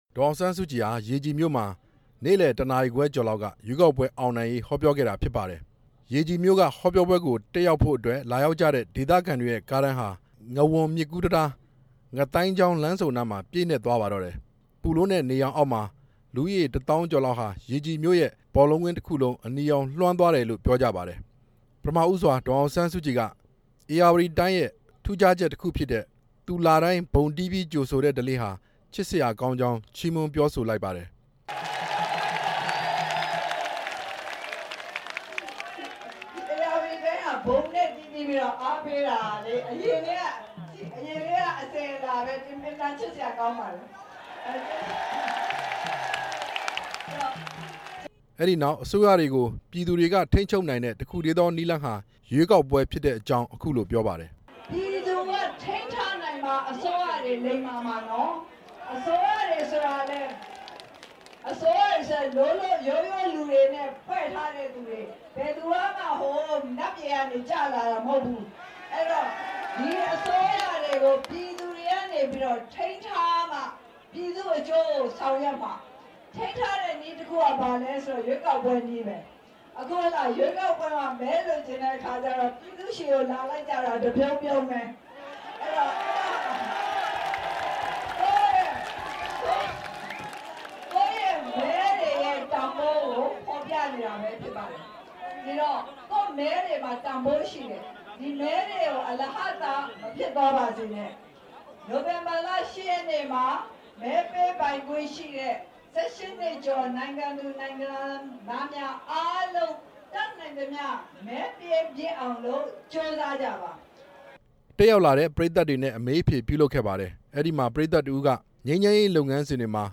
ရေကြည်မြို့ ပြည်သူ့အားကစားကွင်းမှာ ဒီကနေ့ နေ့လယ်ပိုင်းက ဟောပြောခဲ့ရာမှာ အဖွဲ့ချုပ်အနေနဲ့ လာမယ့် ရွေးကောက်ပွဲမှာ မဲလိမ် မဲခိုးကိစ္စ စိုးရိမ်မိကြောင်း၊ မဲပေးတာတွေ မှန်ကန်ဖို့အတွက် လာမယ့် အောက်တိုဘာလ ၂ဝ ရက်နေ့ကစပြီး ဧရာဝတီတိုင်းမှာ မဲဘယ်လိုပေးရမယ်ဆိုတာ သင်တန်းပေးမှာ ဖြစ်ကြောင်း ပြောကြားခဲ့ပါတယ်။